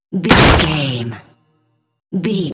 Dramatic hit wood bloody
Sound Effects
Atonal
heavy
intense
dark
aggressive